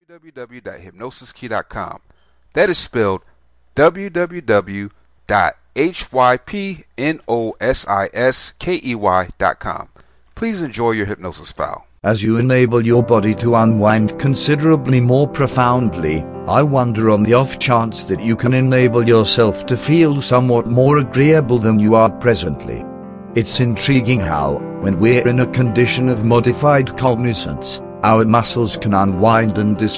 Pain Relaxation Self Hypnosis Script Mp3, this is a powerful hypnosis script that helps you relieve pain.